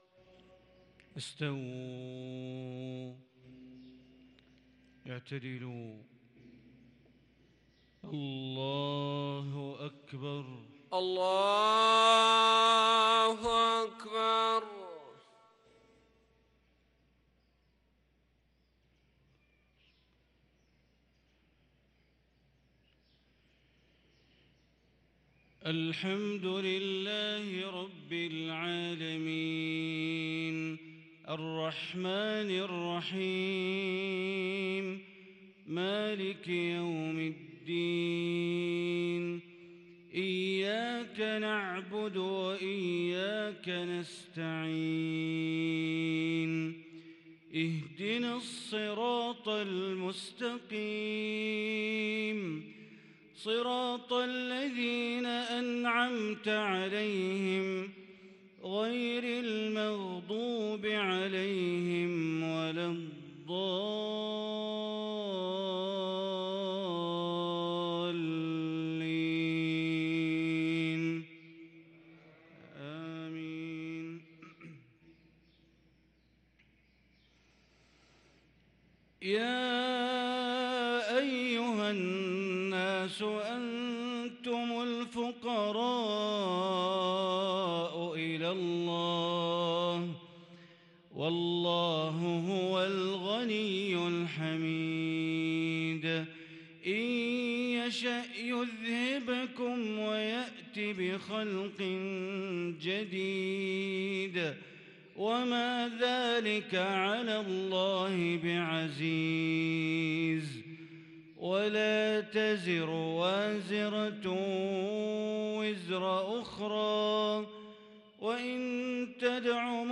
صلاة المغرب للقارئ بندر بليلة 9 جمادي الأول 1444 هـ
تِلَاوَات الْحَرَمَيْن .